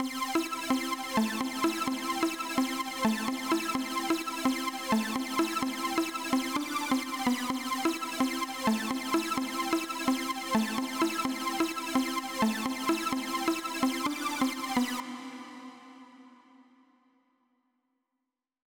VDE 128BPM Notice Melody 2 Root C SC.wav